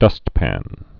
(dŭstpăn)